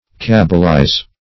Search Result for " cabalize" : The Collaborative International Dictionary of English v.0.48: Cabalize \Cab"a*lize\, v. i. [Cf. F. cabaliser.]